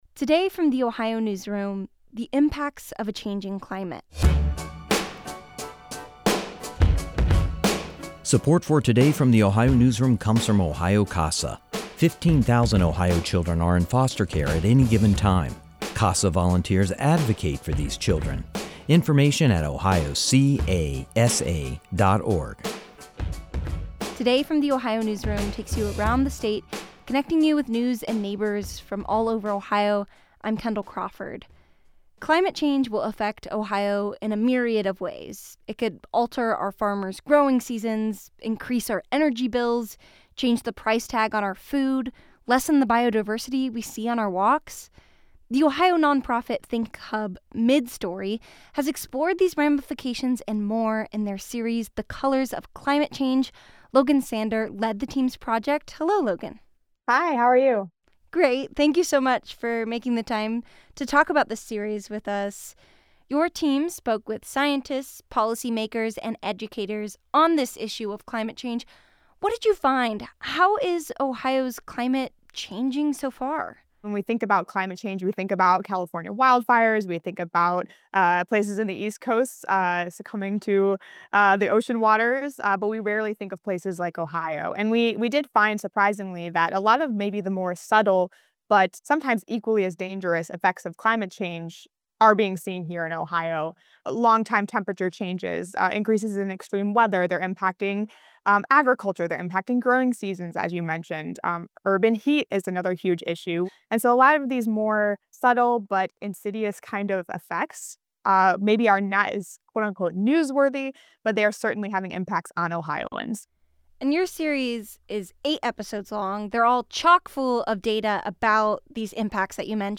This interview has been lightly edited for brevity and clarity.